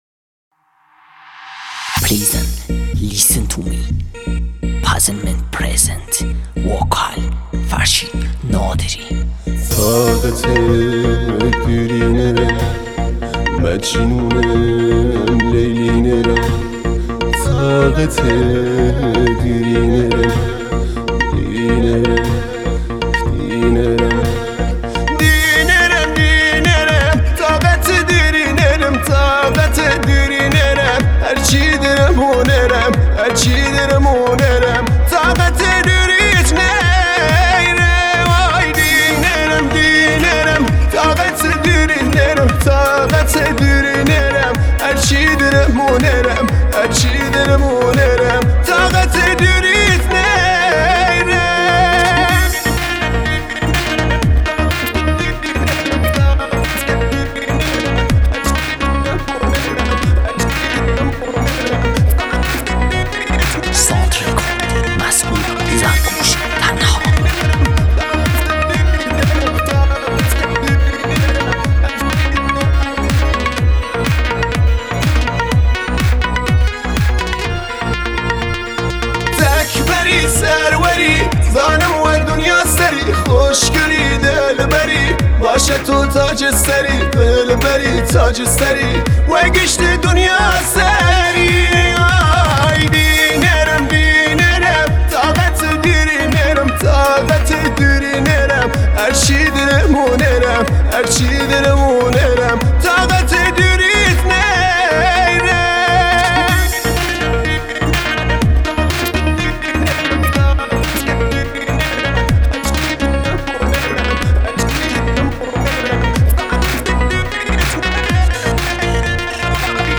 آهنگ کردی بیس دار